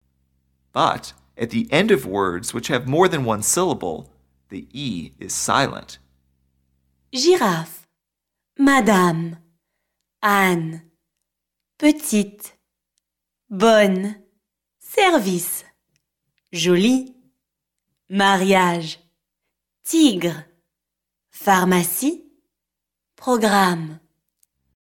é – This accent gives the “e” a different sound, which is a little like the “ay” in the  English word “day.” It is a shorter sound, though, cut off more quickly than in English.
è or ê – These letters sound like “e” in the word “egg.”